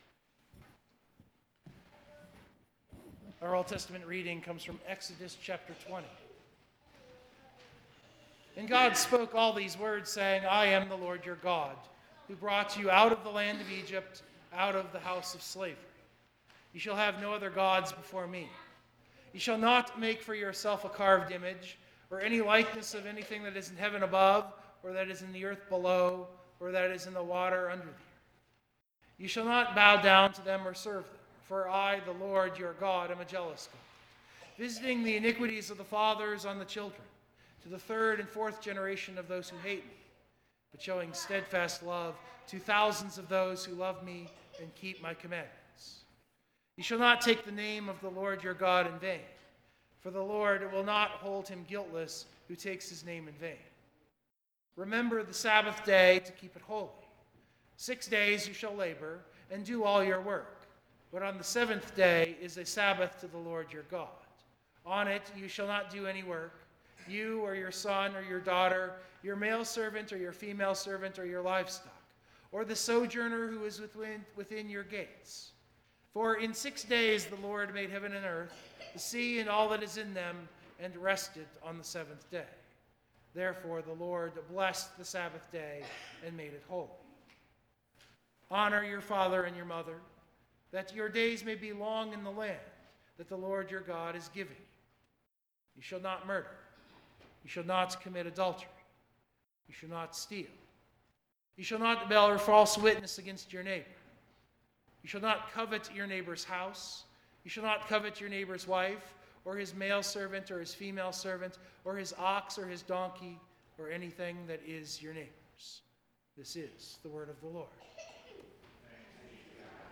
Worship Note: I wish I could have left in the choir, but the recording just didn’t work. (When the men have the strongest line, they get blocked from the main mic. I really need to get the loft mic’d better.) What I did leave in was our opening hymn.